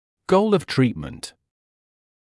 [gəul əv ‘triːtmənt] [гоул эв ‘триːтмэнт] цель лечения